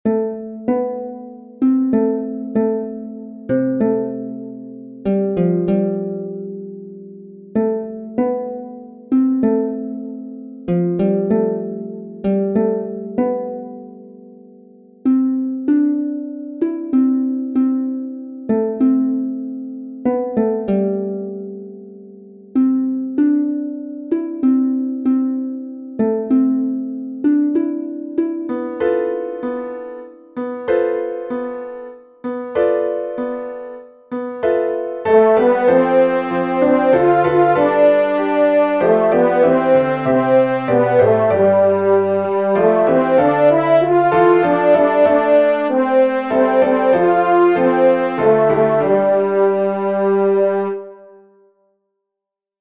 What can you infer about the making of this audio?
Voice part practice (top of page 4 to the top of page 7):